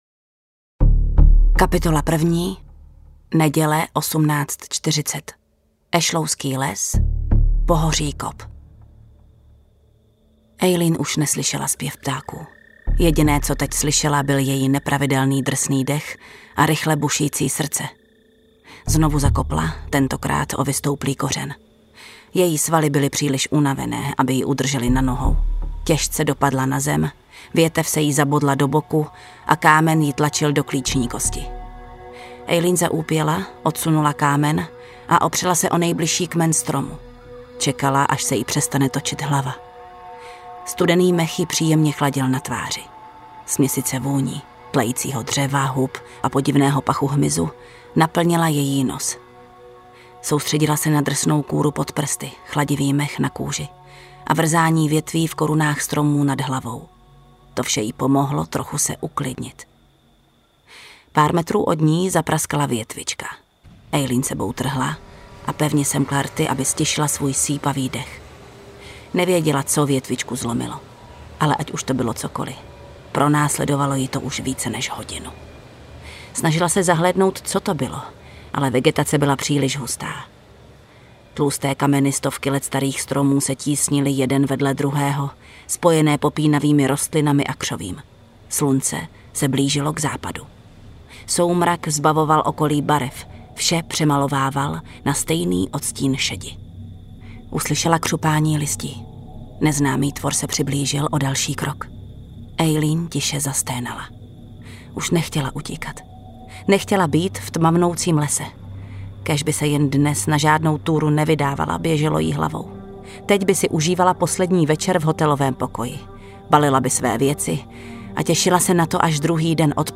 Kořist audiokniha
Ukázka z knihy
• InterpretJana Stryková